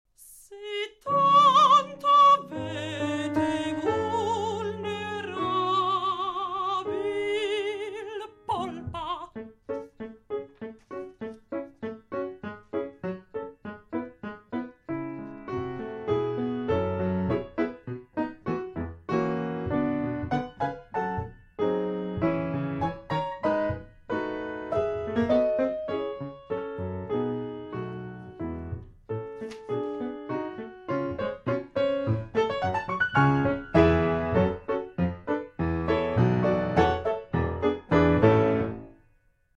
Quand’ero paggio – Cantata